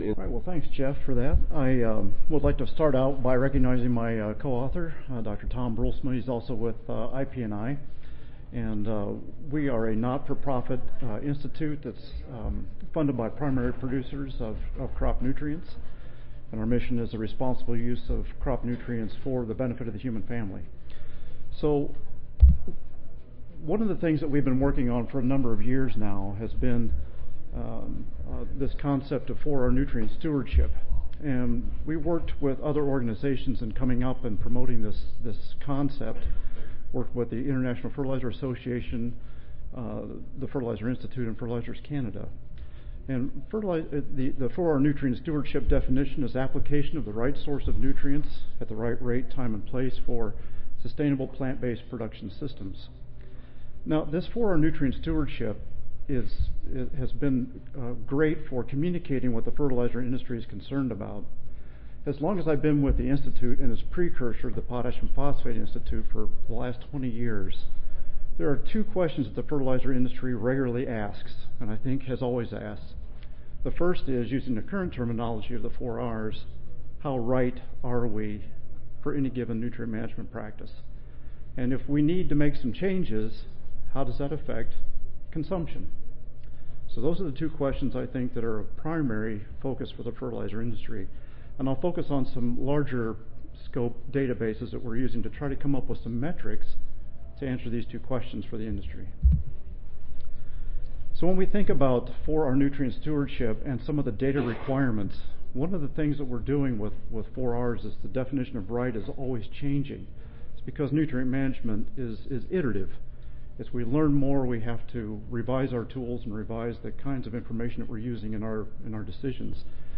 See more from this Division: Special Sessions See more from this Session: Special Session Symposium--The Nutrient Uptake and Outcome Network (NUOnet)
Recorded Presentation